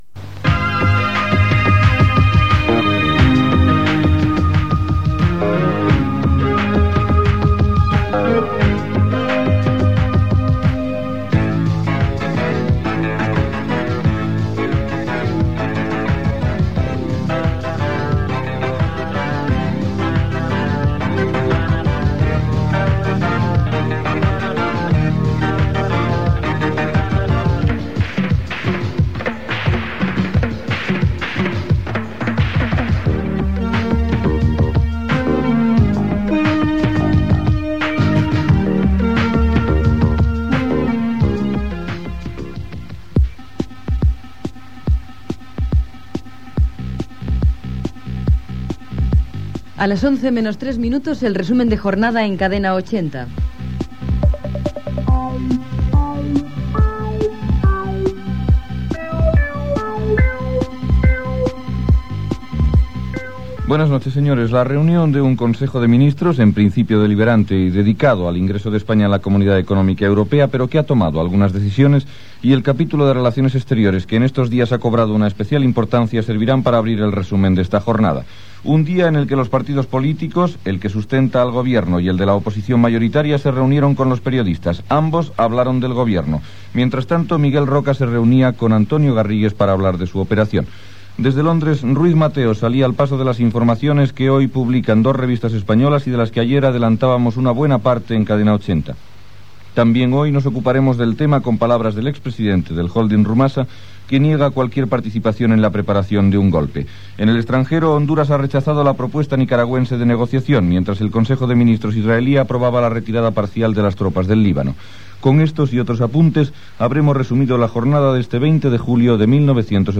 Sintonia de l'emissora i de l'informatiu: adhesió a la Unió Europea d'Espanya, operació Reformista, declaracions de Ruiz Mateos des de Londres.
Informatiu